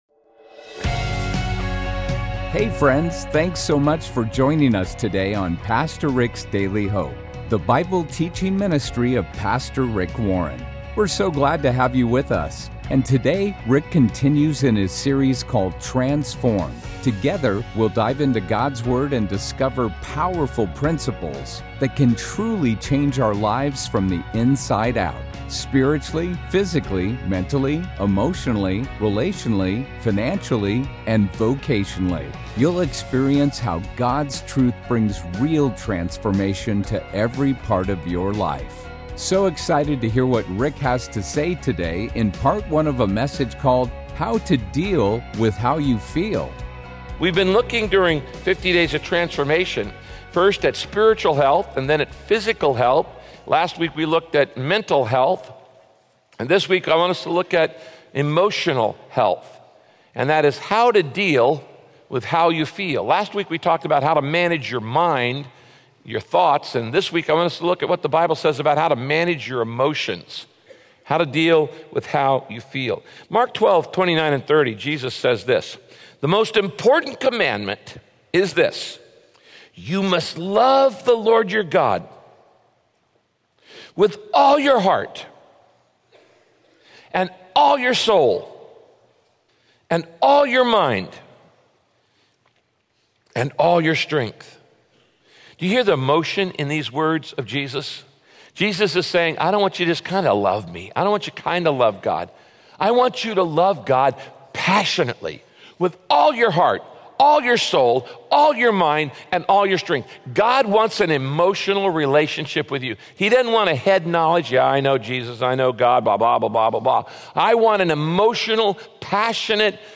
Many people downplay emotions, but God gave you emotions for a reason. Listen to this broadcast by Pastor Rick and discover how God wants you to use your emotio…